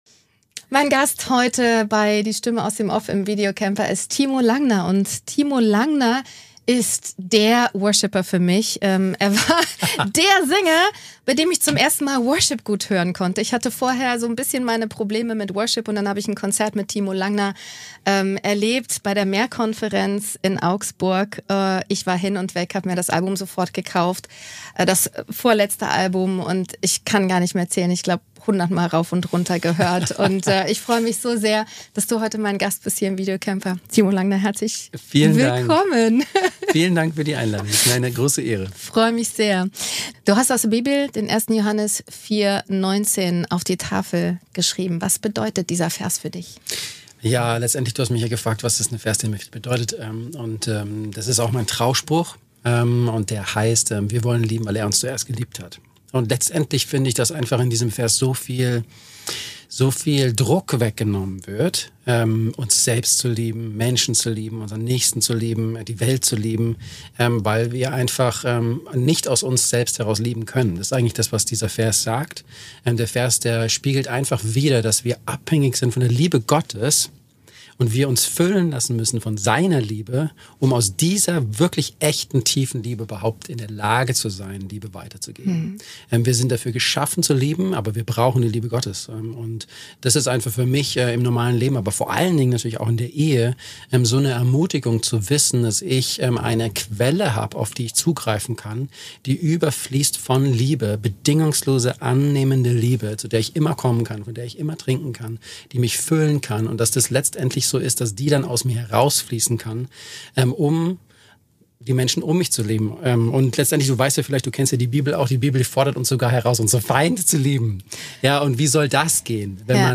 dann ist dieses Gespräch für dich
Der Ton ist top.